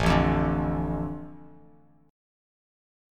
Asus4#5 chord